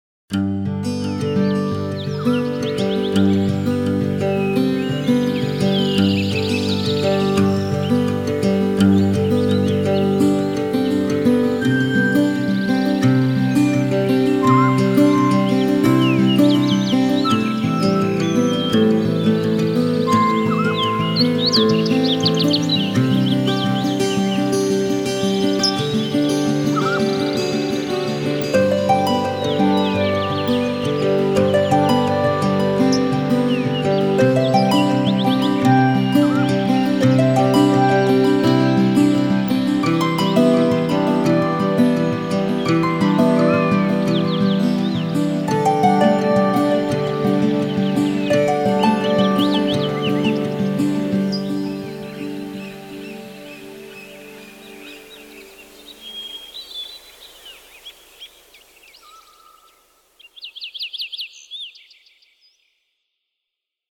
Cuckoo.ogg